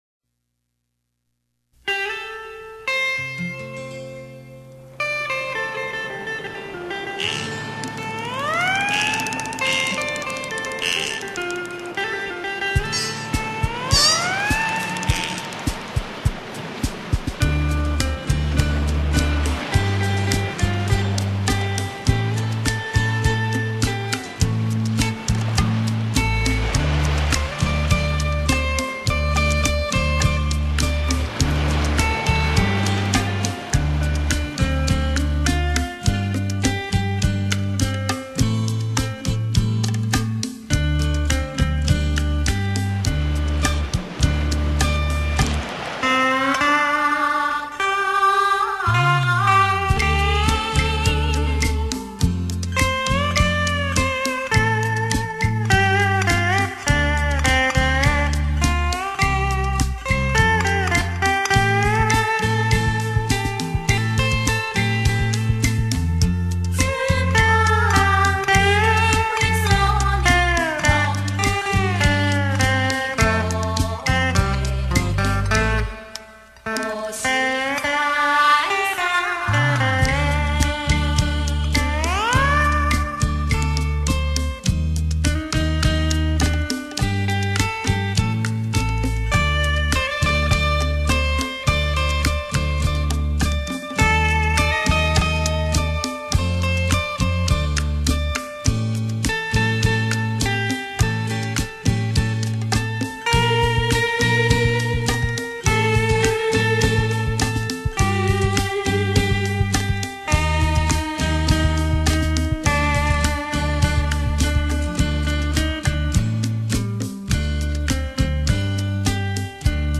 优美的琴声，令人心旷神怡的遐想......